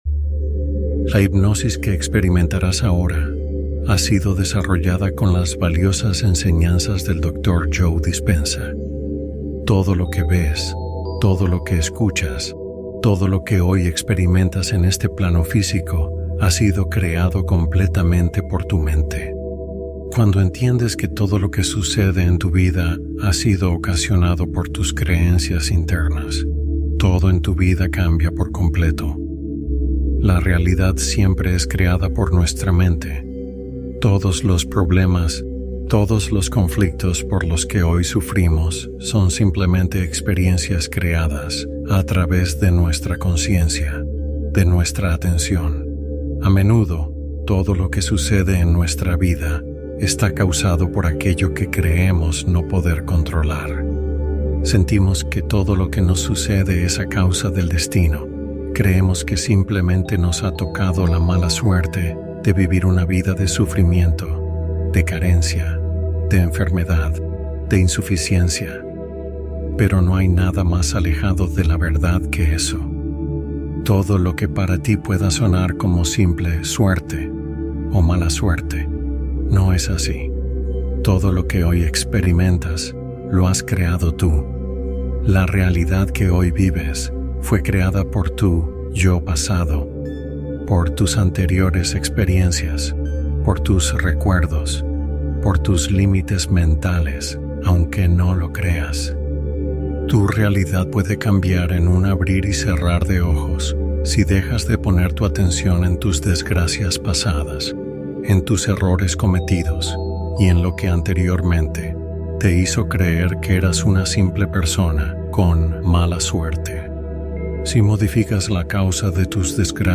Lo Imposible Comienza Mañana | Meditación de Alineación